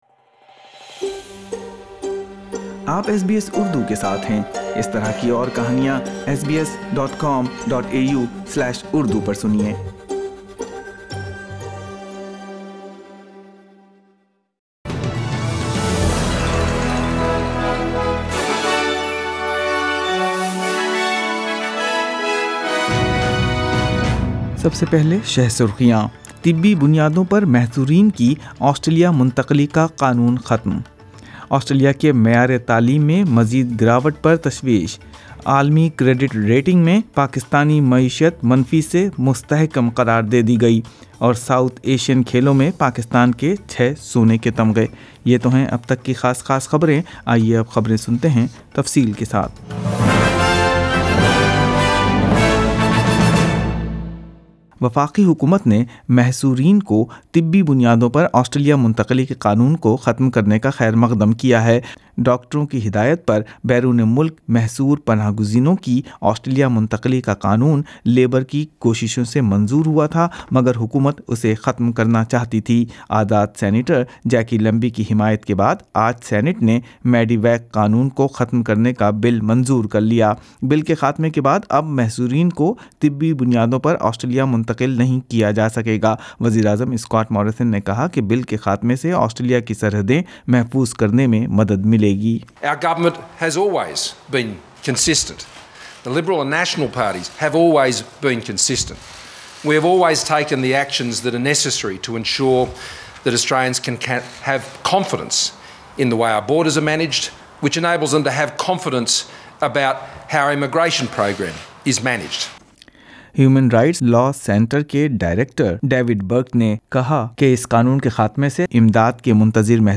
اردو خبریں پانچ دسمبر ۲۰۱۹